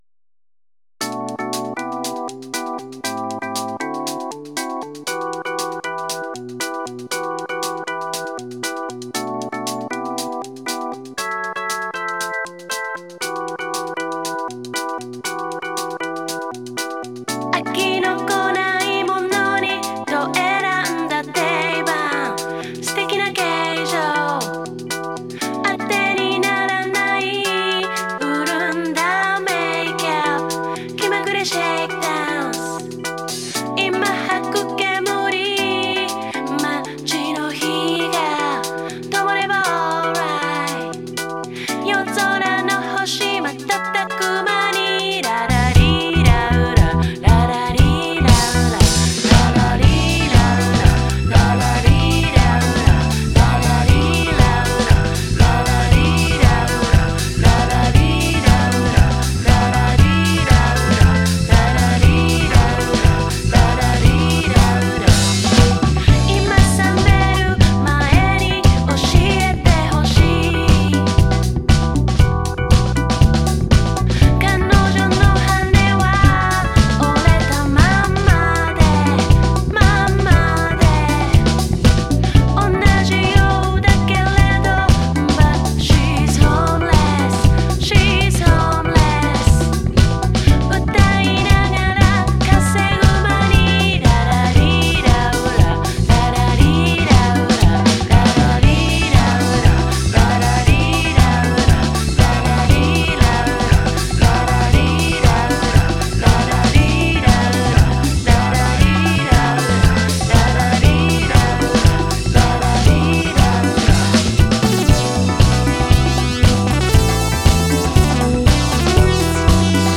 ジャンル(スタイル) JAPANESE POP